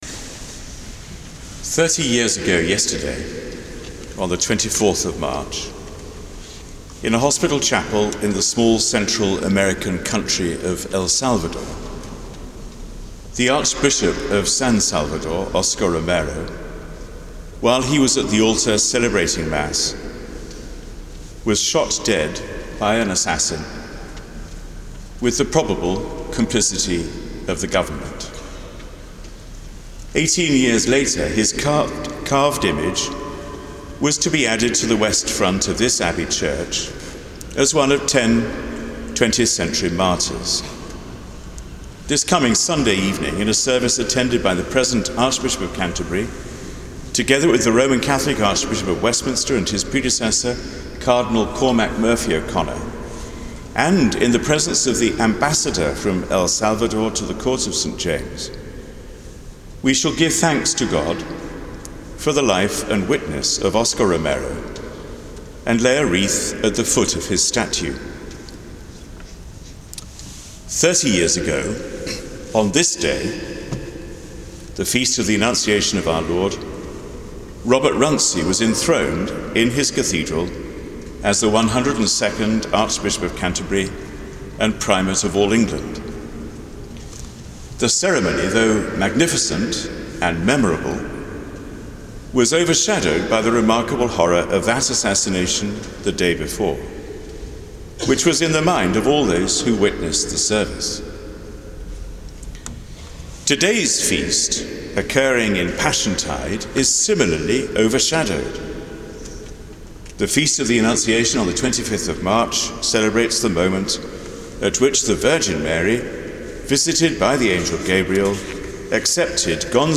Sermon given at Sung Eucharist on The Annunciation of our Lord: Thursday 25 March 2010